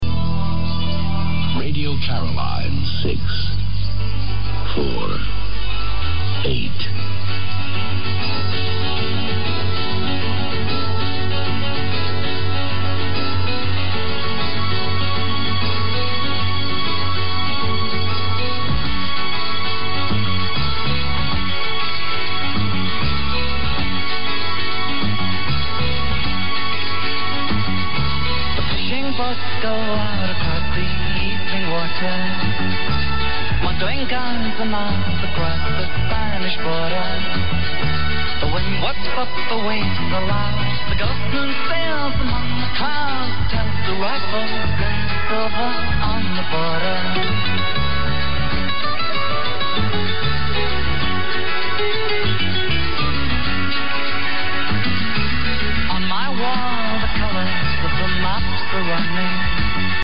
Et malgré sa porteuse inférieure, Caroline possède une bonne et forte modulation qui fait presque jeu égale avec ses voisines. Un peu de brouillage cependant par moment, par RNE en Espagne aussi, même fréquence.
Vers 18h45, Caroline a dépassé mes deux stations espagnoles.